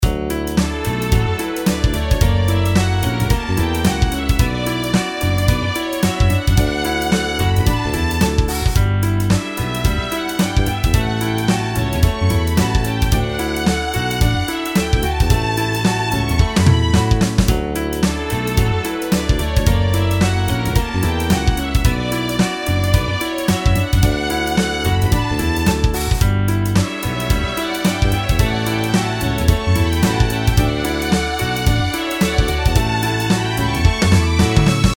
楽曲の曲調： SOFT